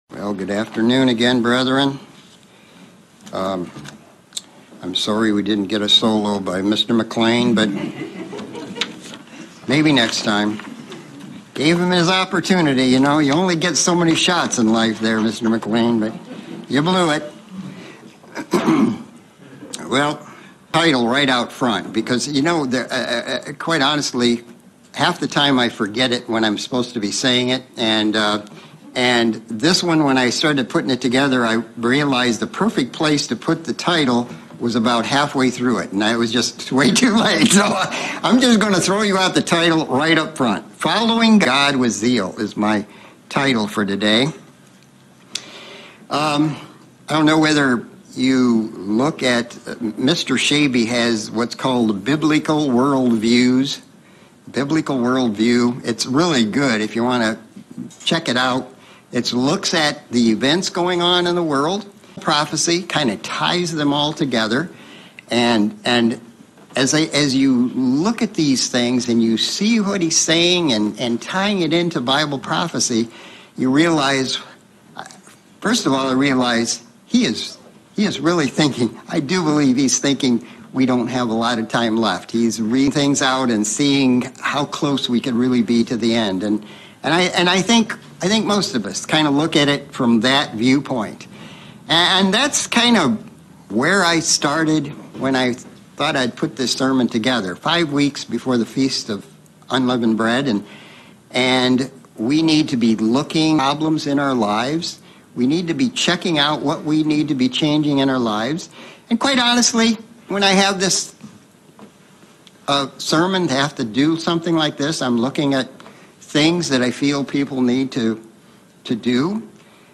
Sermon looking at the people of the Bible, Israel and they they will follow God with Zeal after the return of Christ. Leveraging their example we can draw lessons from them as we too strive to follow God with zeal.